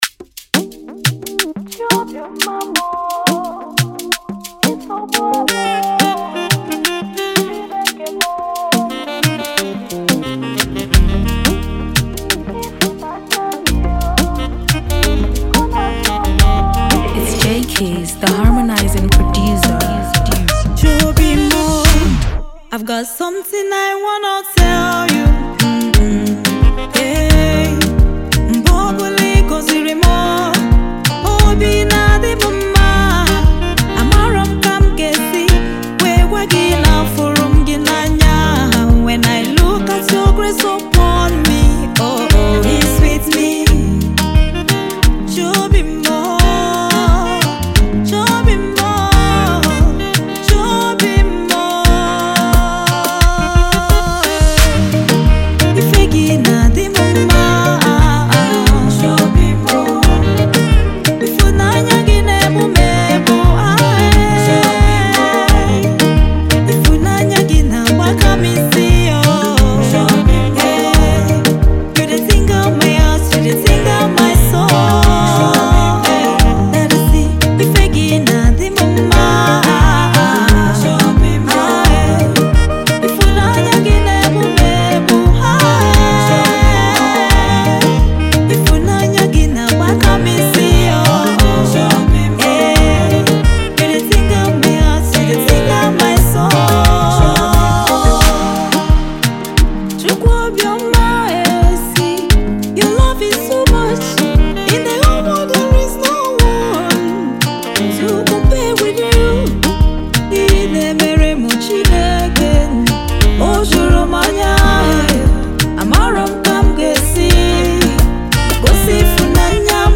is a Nigerian gospel singer